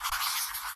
Minecraft Version Minecraft Version 25w18a Latest Release | Latest Snapshot 25w18a / assets / minecraft / sounds / ui / cartography_table / drawmap3.ogg Compare With Compare With Latest Release | Latest Snapshot
drawmap3.ogg